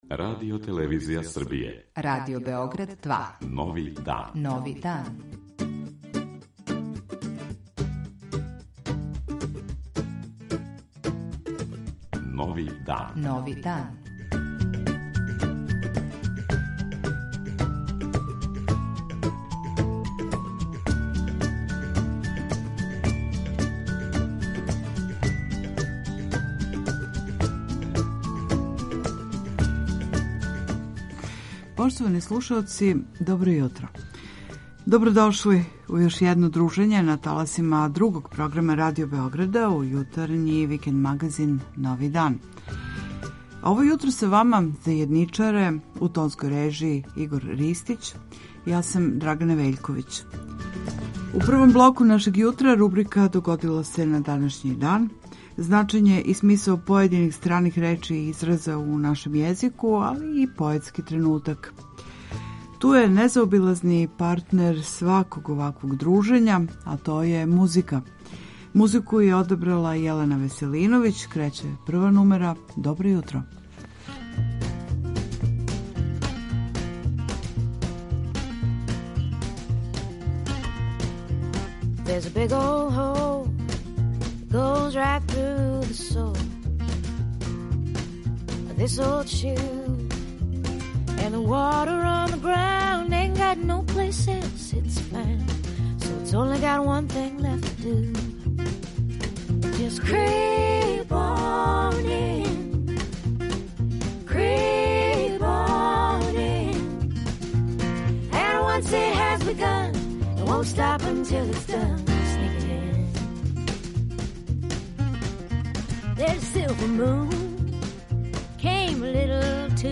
Гост књижевник